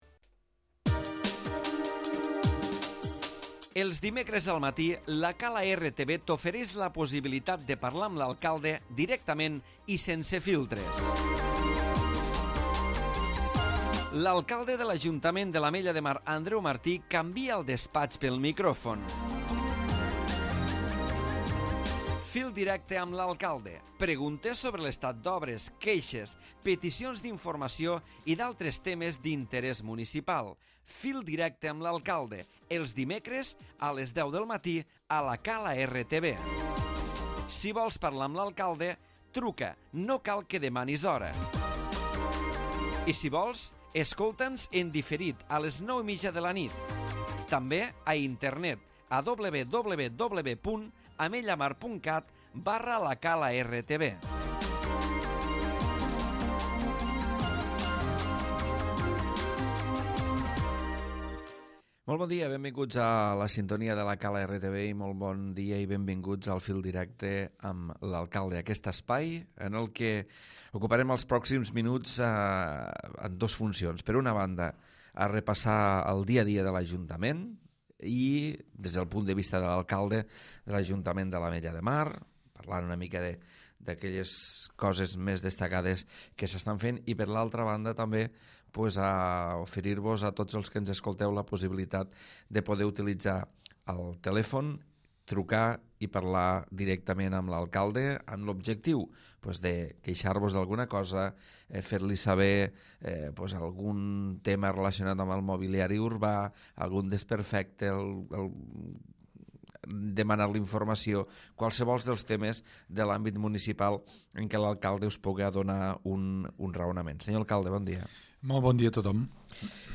L'Alcalde Andreu Martí repassa el dia a dia de l'Ajuntament amb el telèfon obert a les trucades dels ciutadans.